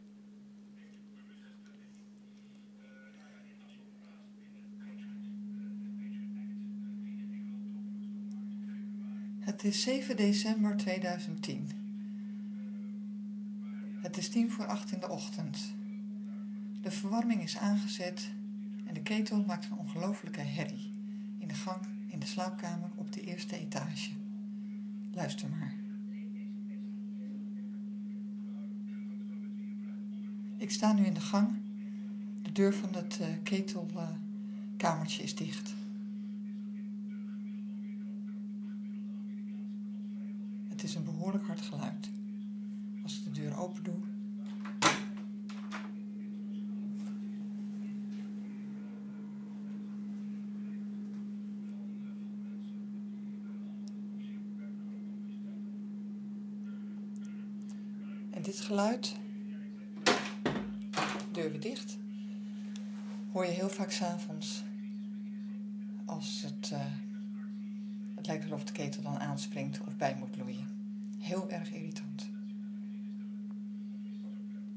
Verwarmingsketel maakt herrie